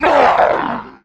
Hiệu ứng âm thanh Tiếng Hét, Gầm rú của thây ma trước khi chết - Tải Mp3